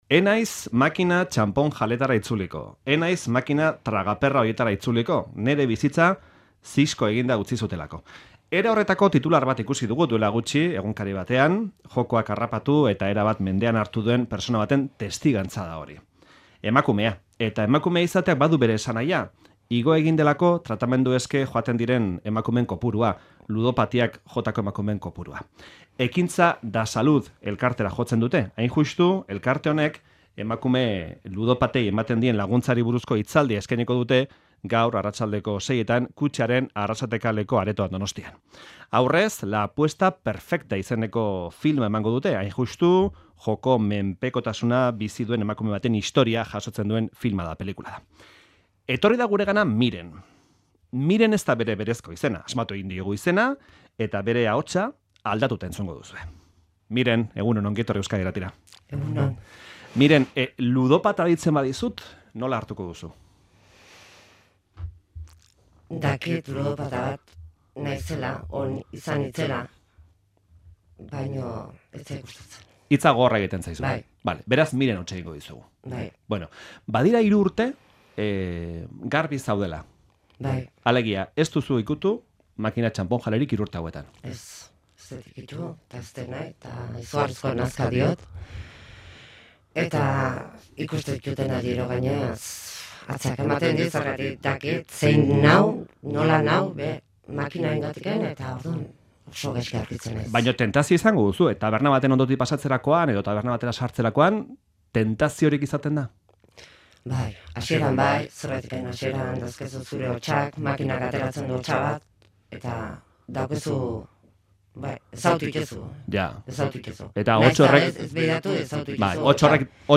Ludopatia dela eta, tratamendu eske Ekintza Dasalud elkartera joaten diren emakumeen kopuruak gora egin du. Ludotapa den emakume batekin hitz egin dugu.